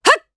Glenwys-Vox_Attack1_jp.wav